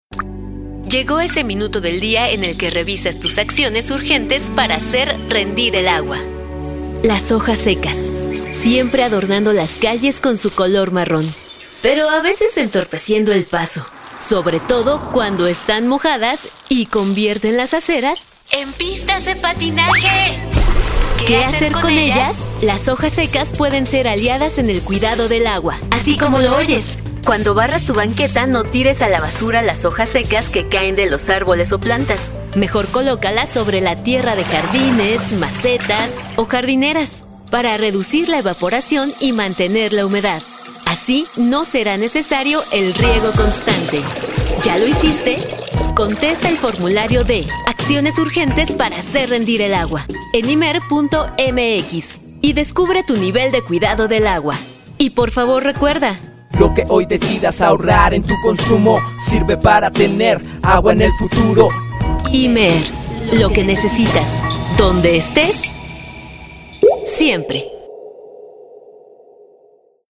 ACTUACIÓN DRAMÁTICA